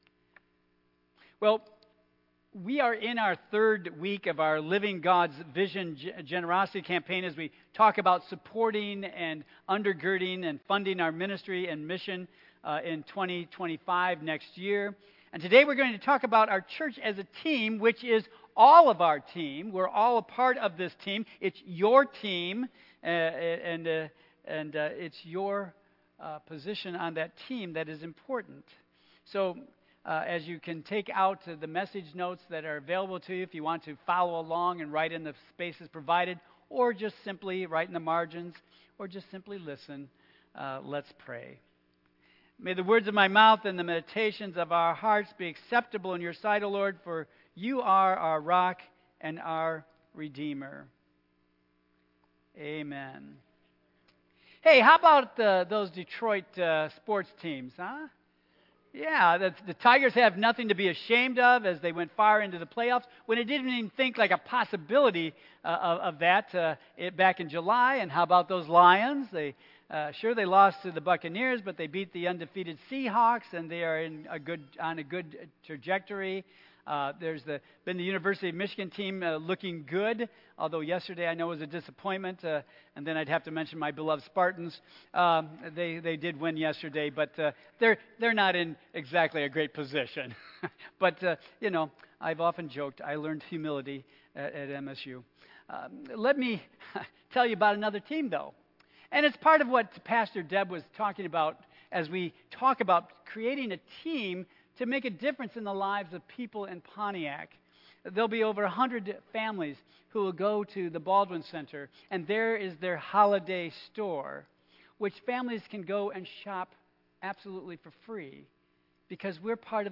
Tagged with Michigan , Sermon , Waterford Central United Methodist Church , Worship Audio (MP3) 10 MB Previous Your Calling Next Your Impact